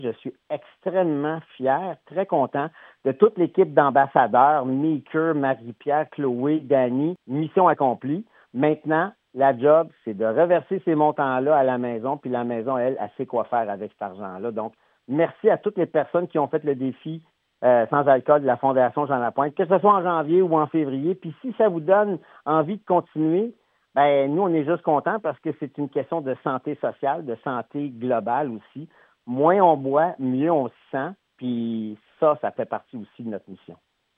Jean Marie Lapointe, porte-parole de la Fondation Jean Lapointe se dit fier de cette édition.